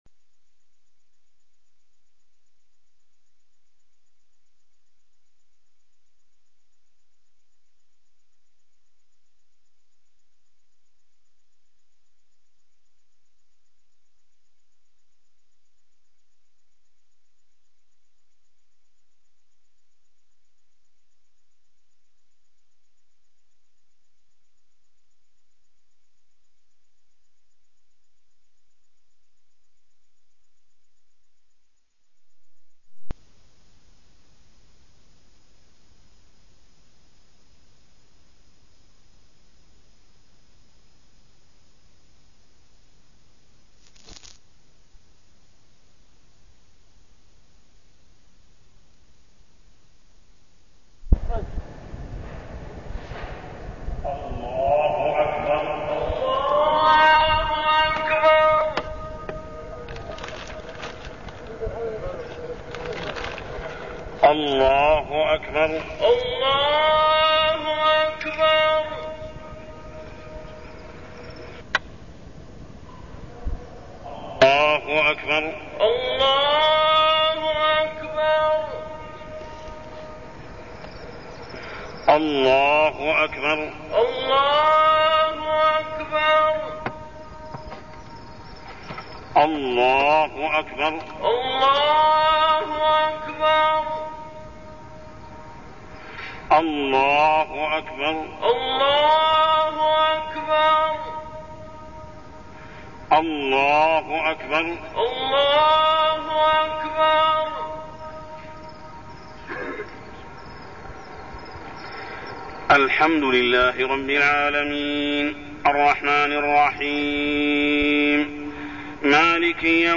تاريخ النشر ٢ رجب ١٤١٤ هـ المكان: المسجد الحرام الشيخ: محمد بن عبد الله السبيل محمد بن عبد الله السبيل رفع الدين في الإستسقاء